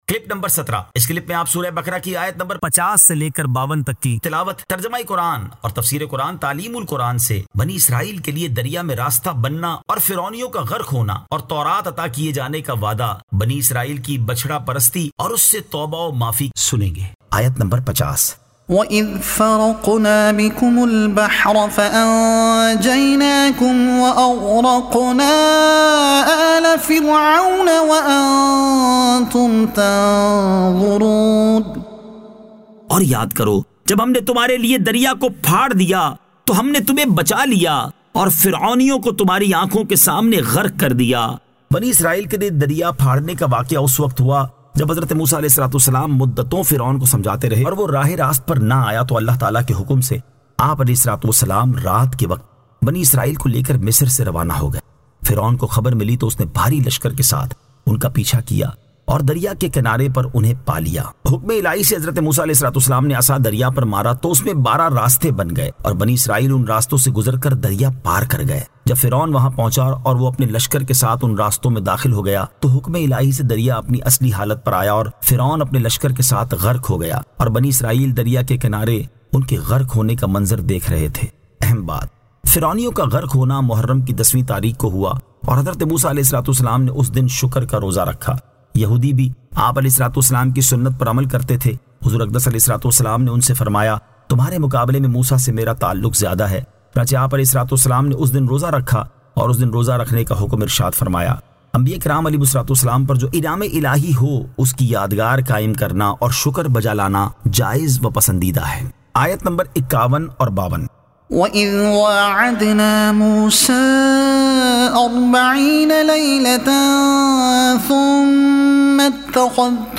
Surah Al-Baqara Ayat 50 To 52 Tilawat , Tarjuma , Tafseer e Taleem ul Quran